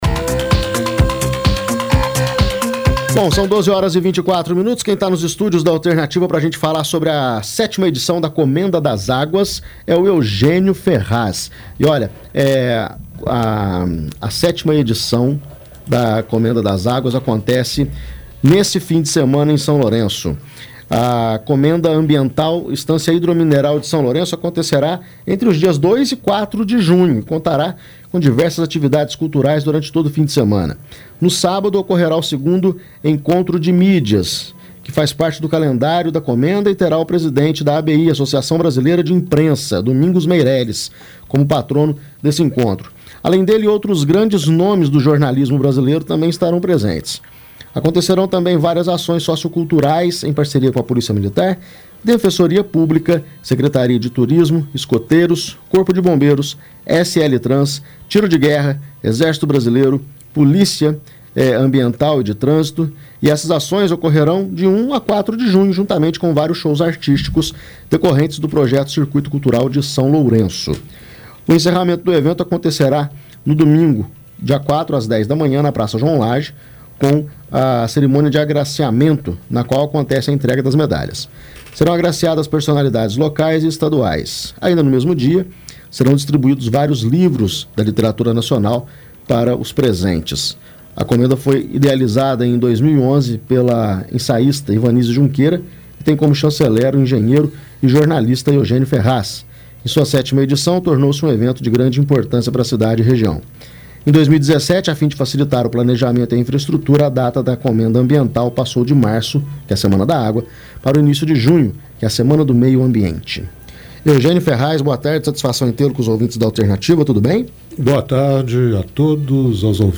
ENTREVISTA COM O CHANCELER | RÁDIO ALTERNATIVA